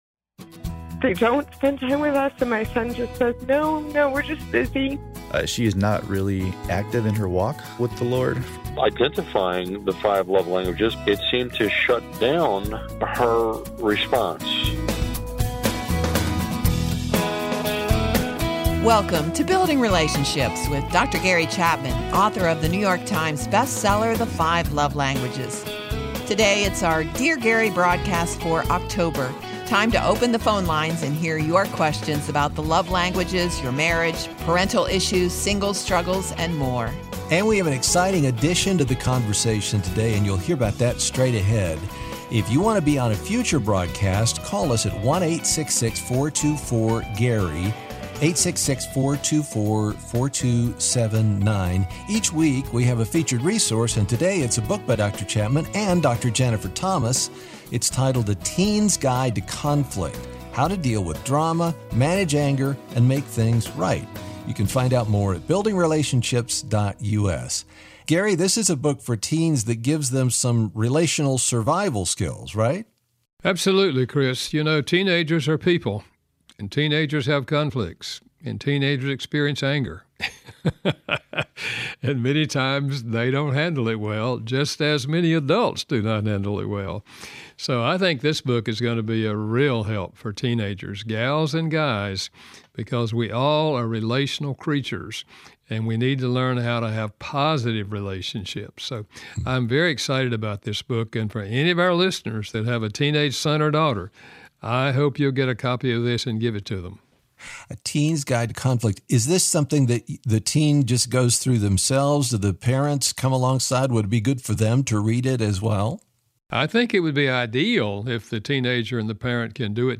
When Dr. Gary Chapman takes questions from listeners, good things happen.